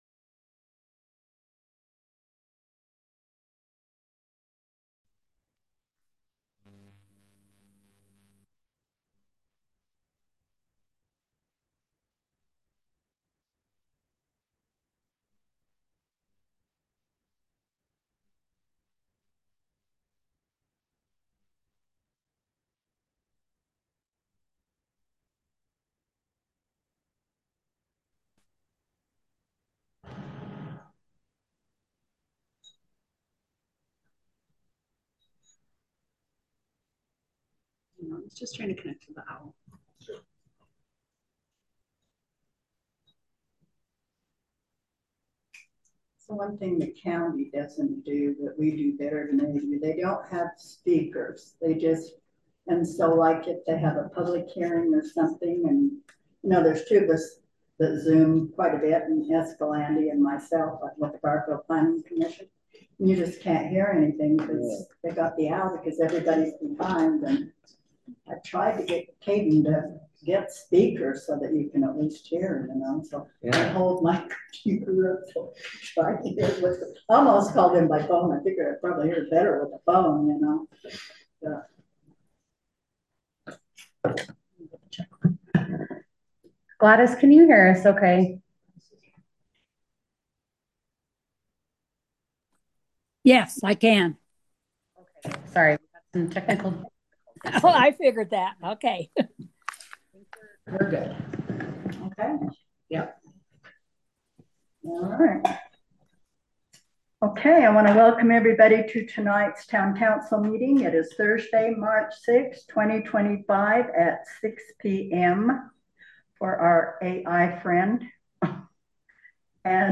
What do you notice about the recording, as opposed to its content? The Boulder Town Council will hold its regular meeting on Thursday March 6, 2025, starting at 6:00 pm at the Boulder Community Center Meeting Room, 351 No 100 East, Boulder, UT. Zoom connection will also be available.